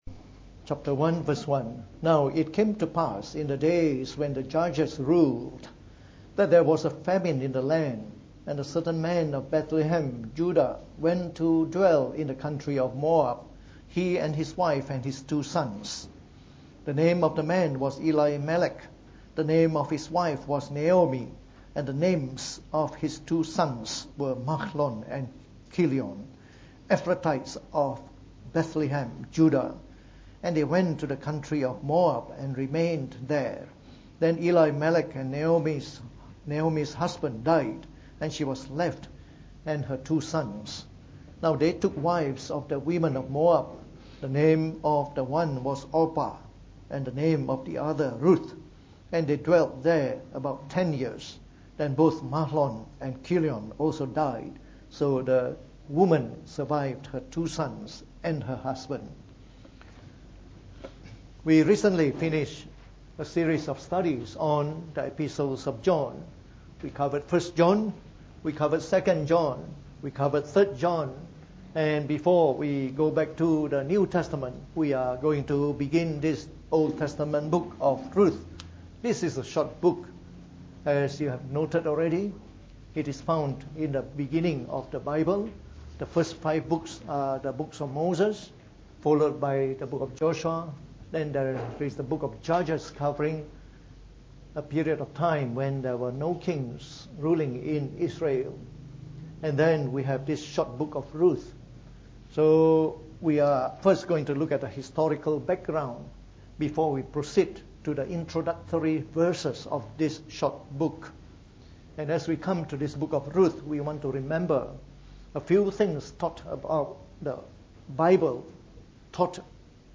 From our new series on the Book of Ruth delivered in the Morning Service.